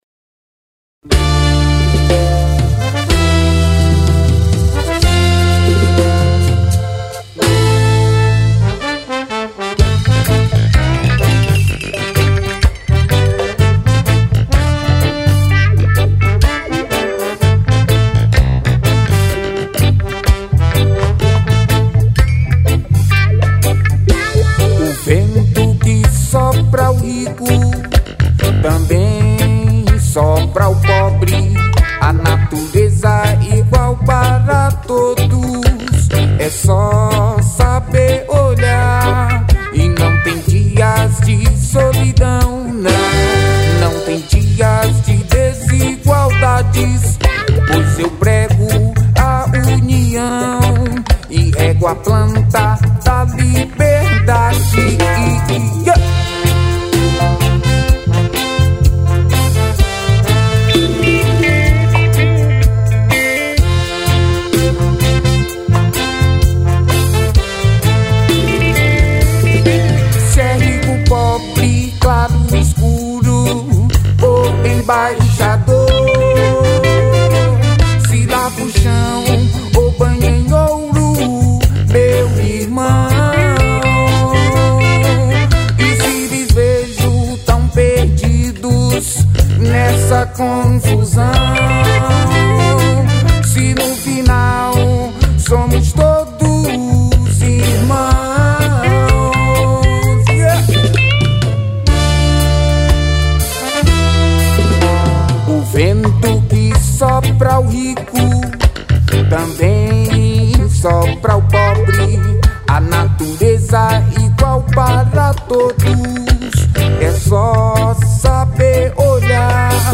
04:06:00   Reggae